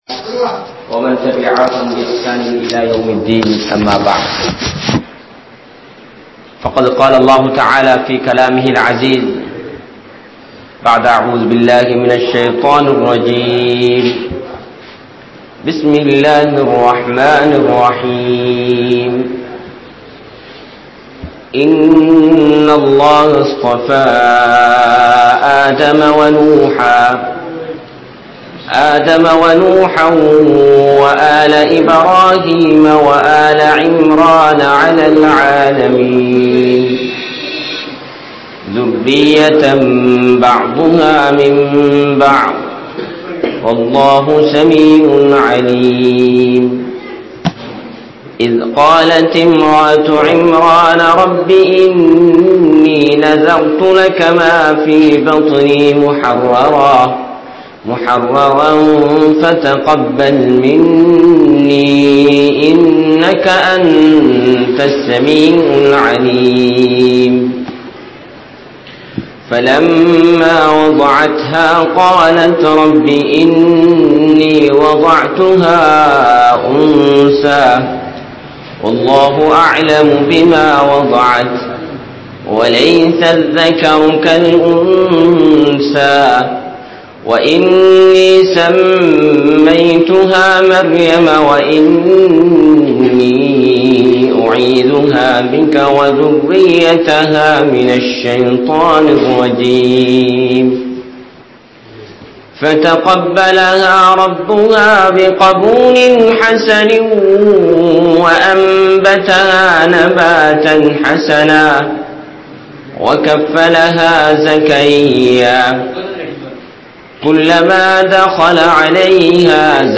Mun Maathiriyaana Islamiya Pengal (முன்மாதிரியான இஸ்லாமிய பெண்கள்) | Audio Bayans | All Ceylon Muslim Youth Community | Addalaichenai